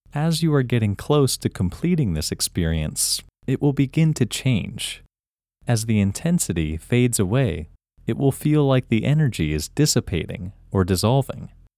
IN – First Way – English Male 19